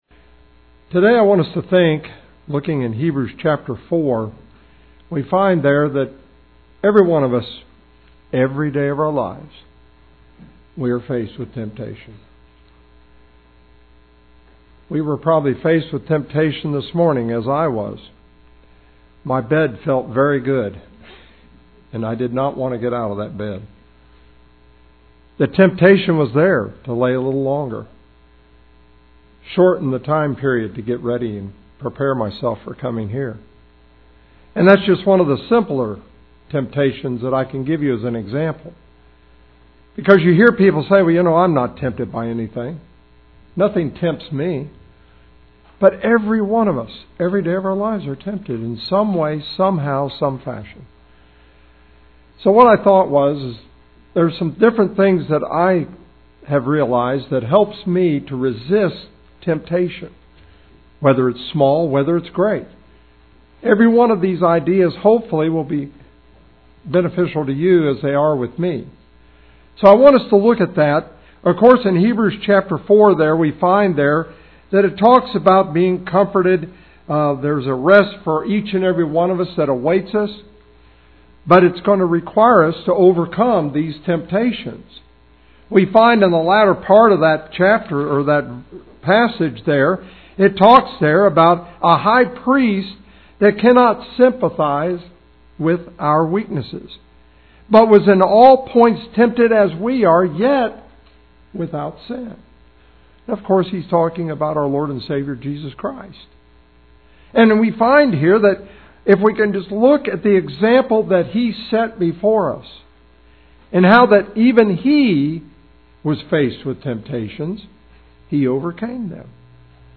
Lesson Recording